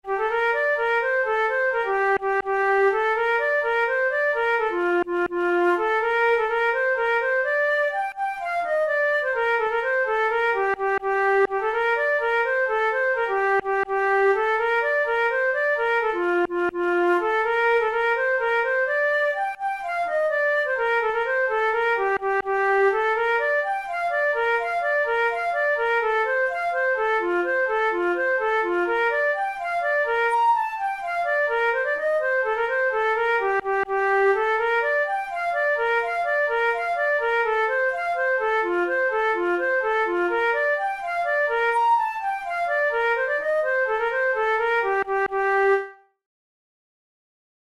InstrumentationFlute solo
KeyG minor
Time signature6/8
Tempo84 BPM
Jigs, Traditional/Folk
Traditional Irish jig